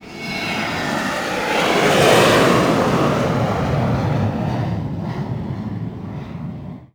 VEC3 FX Athmosphere 16.wav